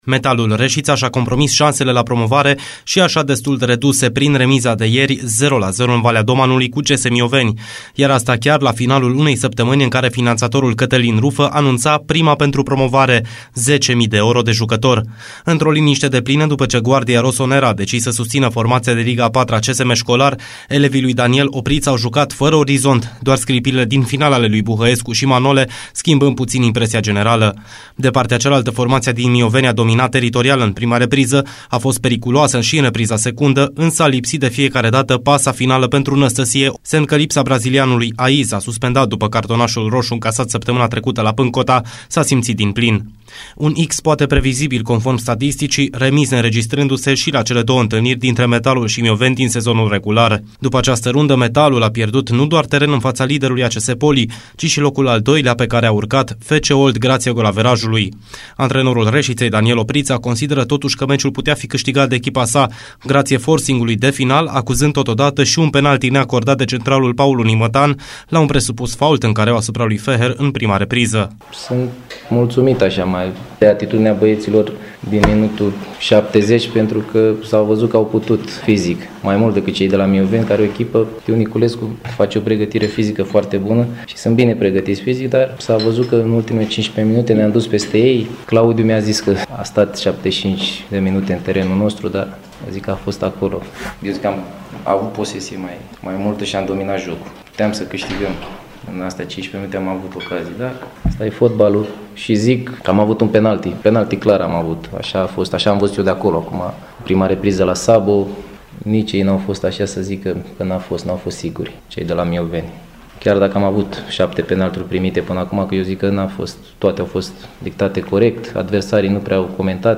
Detalii și reacții de la partida disputată ieri în Valea Domanului, în următorul reportaj